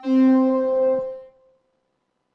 描述：通过Behringer调音台采样到MPC 1000。它听起来一点也不像风的部分，因此被称为Broken Wind。
Tag: 模拟 多样品 合成器 虚拟模拟